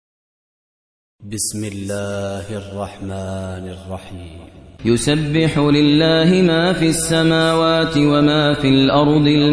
62. Surah Al-Jumu'ah سورة الجمعة Audio Quran Tarteel Recitation
Surah Repeating تكرار السورة Download Surah حمّل السورة Reciting Murattalah Audio for 62.